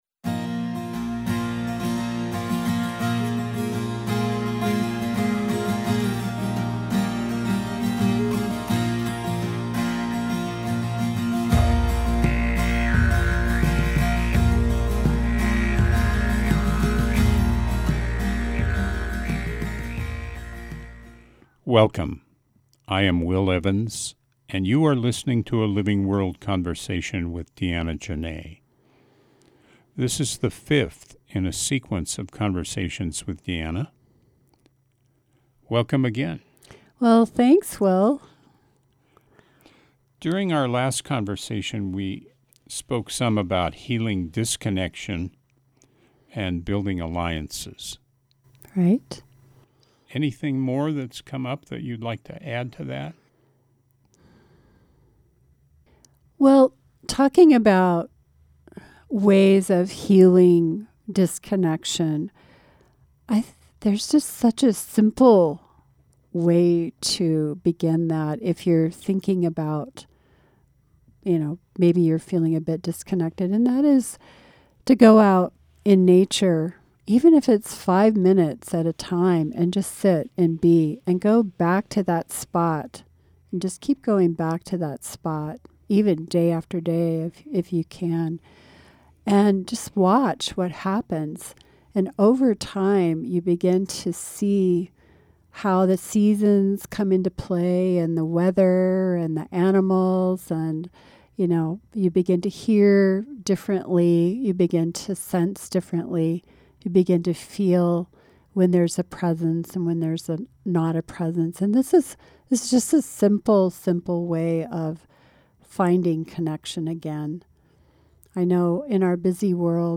Shifting Gears features conversations with people making life-sustaining choices.